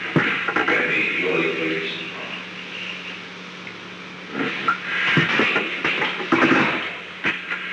Secret White House Tapes
Location: Oval Office
The President talked with the White House operator